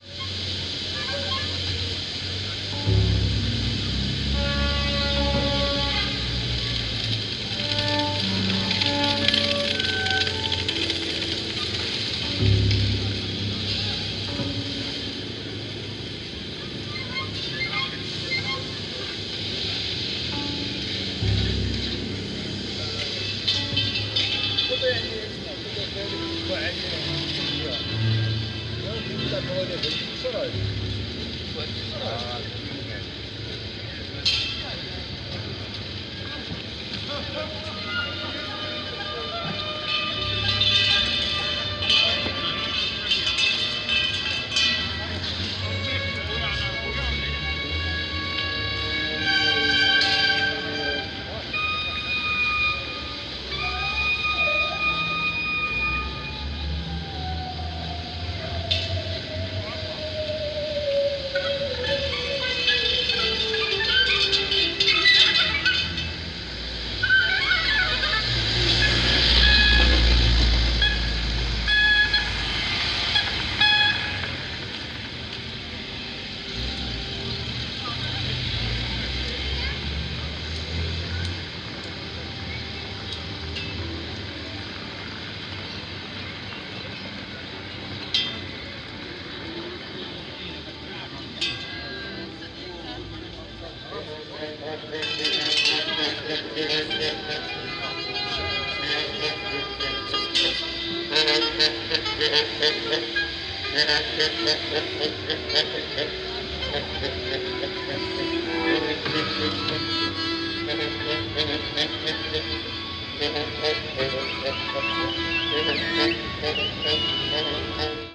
improvisation in large ensembles